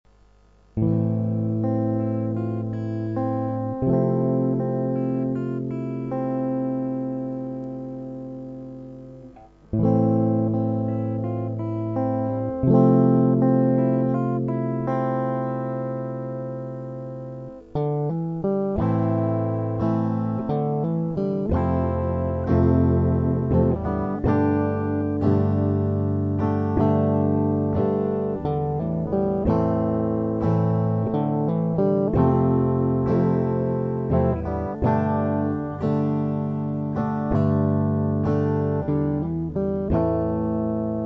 mp3 - вступление и куплет